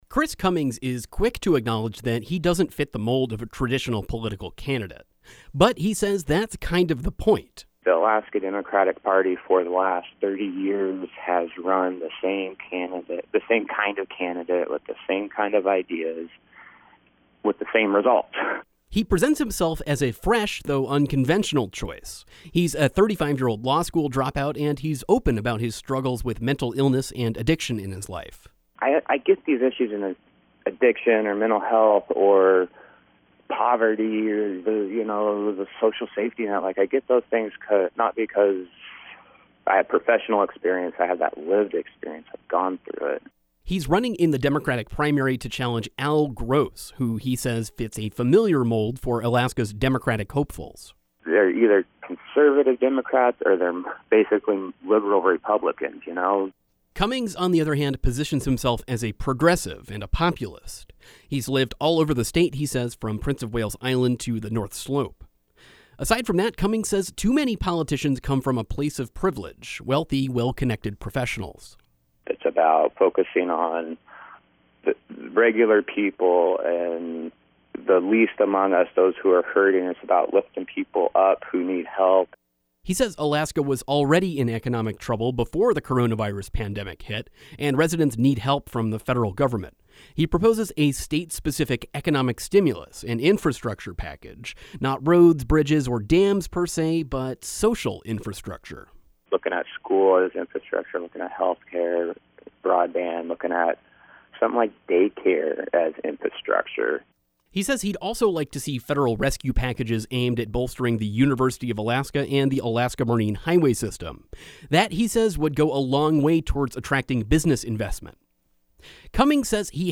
“The Alaska Democratic Party for the last 30 years has run […] the same kind of candidate with the same kind of ideas — with the same result,” he told KRBD in a phone interview Wednesday.